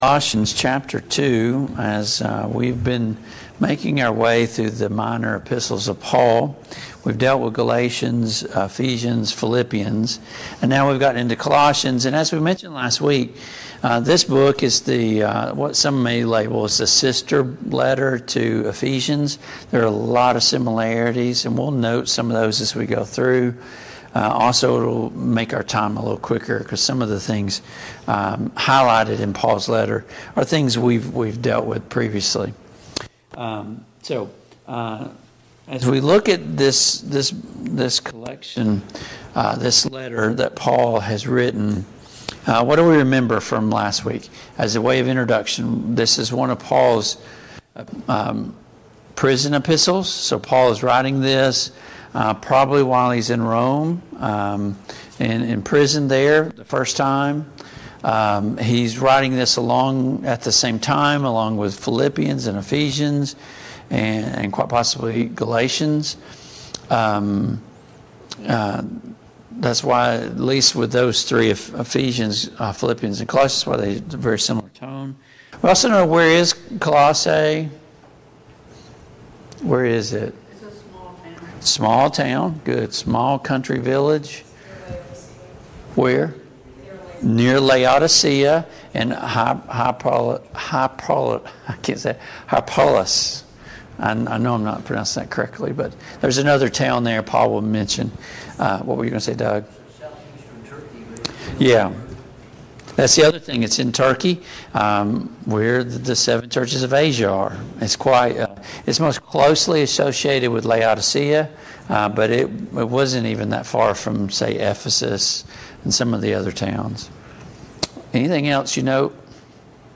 Study of Paul's Minor Epistles Passage: Colossians 1:1-14 Service Type: Mid-Week Bible Study Download Files Notes « 37.